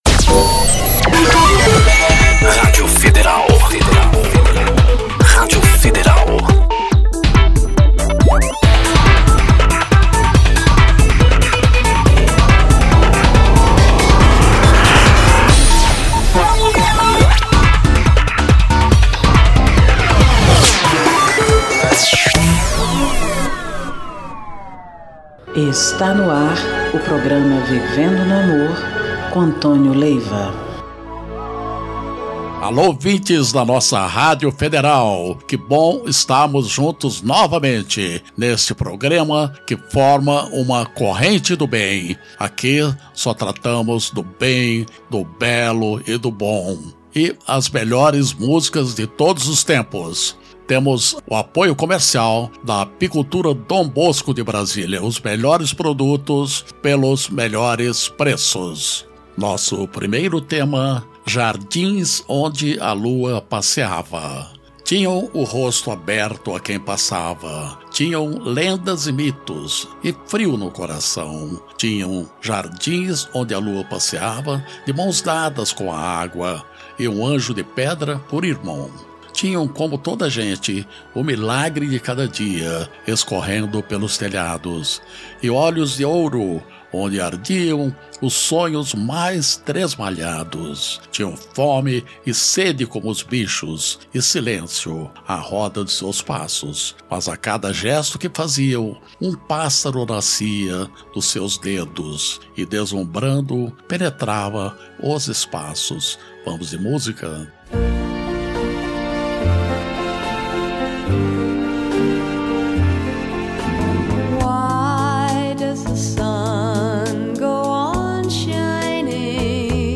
MÚSICAS E MENSAGENS